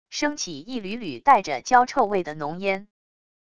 升起一缕缕带着焦臭味的浓烟wav音频生成系统WAV Audio Player